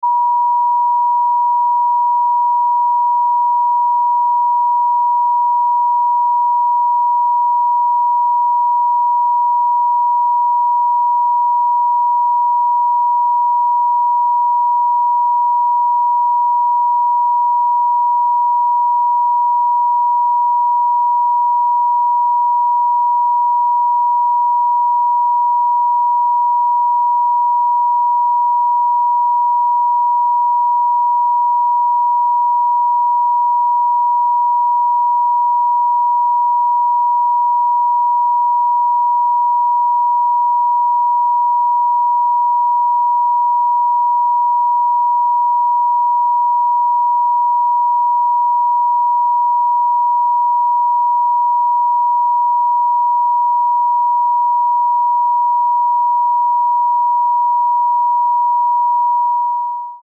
963 HZ SOLFEGGIO PURE TONE sound effects free download
963 HZ SOLFEGGIO PURE TONE FREQUENCY